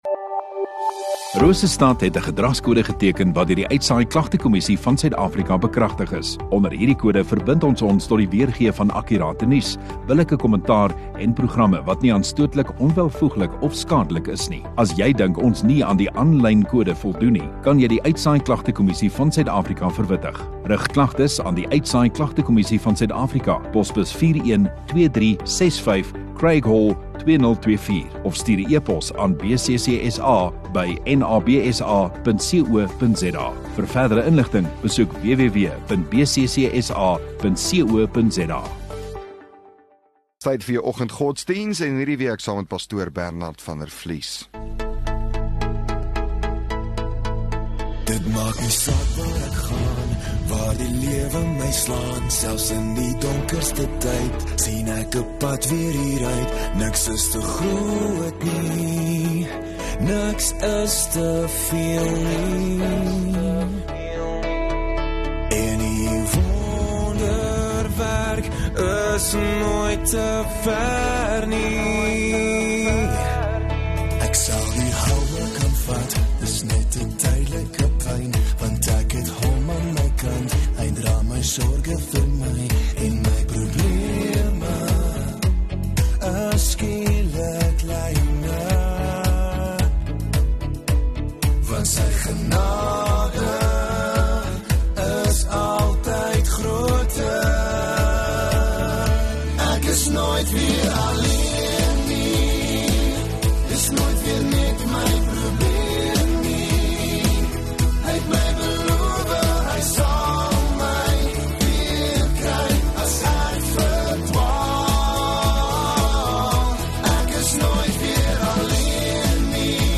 21 Jul Maandag Oggenddiens